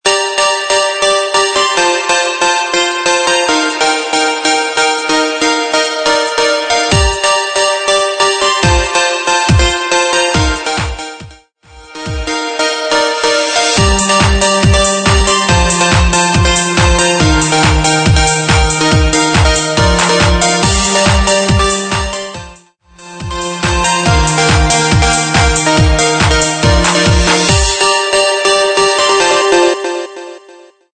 140 BPM
Electronic